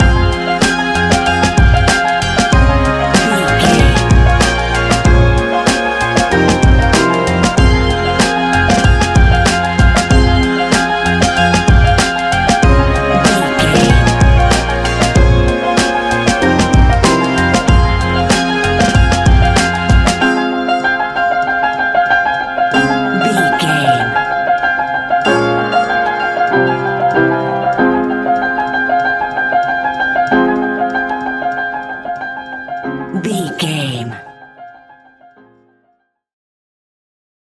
Ionian/Major
F♯
laid back
Lounge
sparse
new age
chilled electronica
ambient
atmospheric
instrumentals